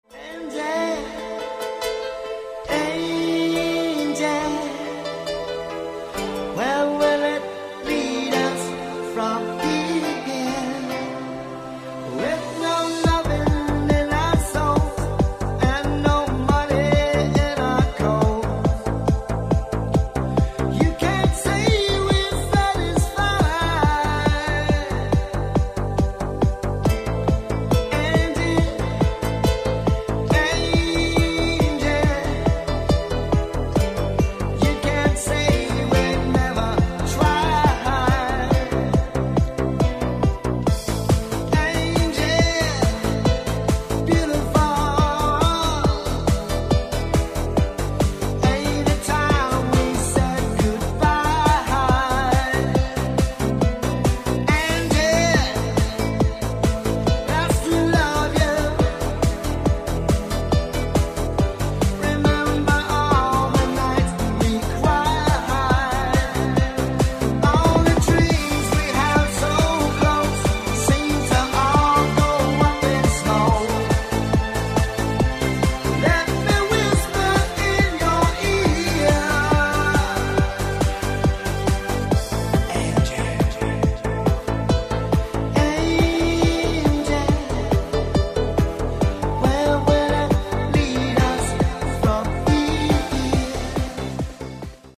мужской вокал
dance
электронная музыка
клавишные
Cover
house